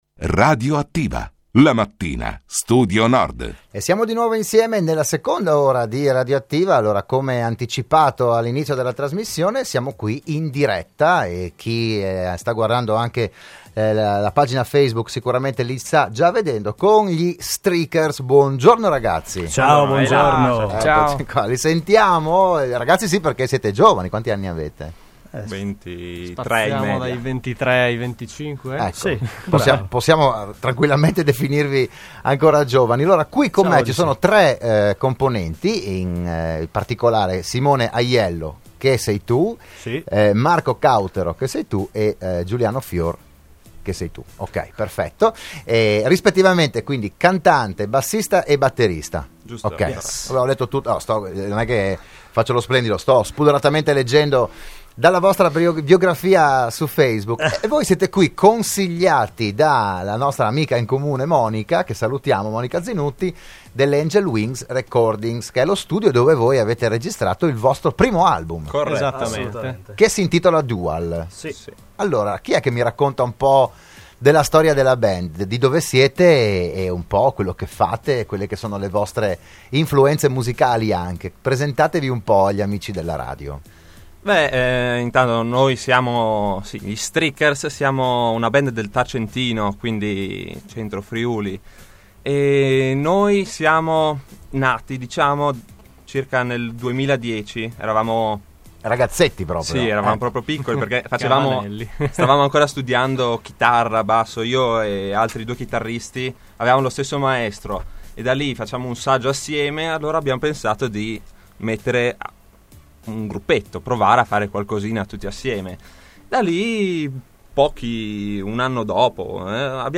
Tre componenti della band hard-rock di Tarcento hanno partecipato oggi a "RadioAttiva", la trasmissione del mattino